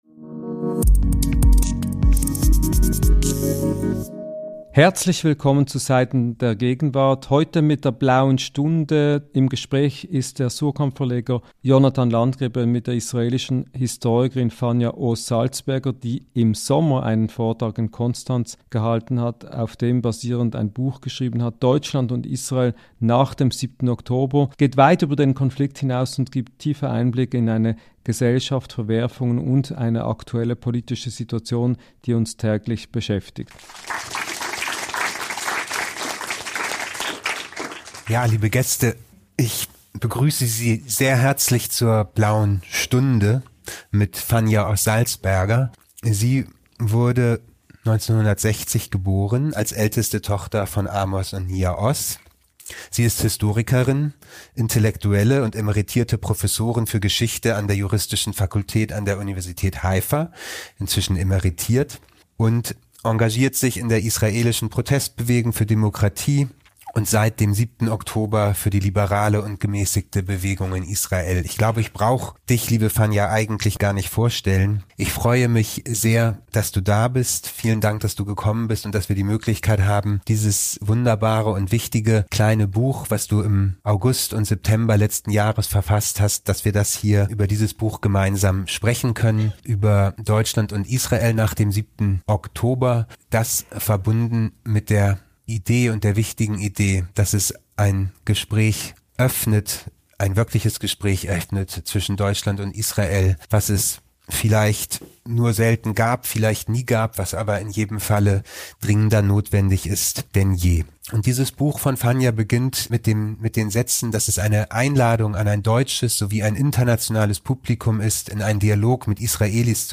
Gespräche über Literatur & Gesellschaft Podcast